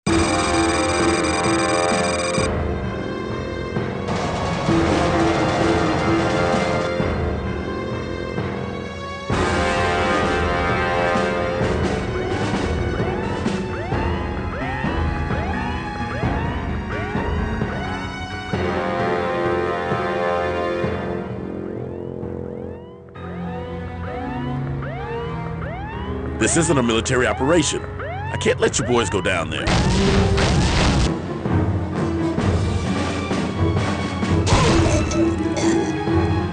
Максимальный семплинг — всего лишь 22 кГц!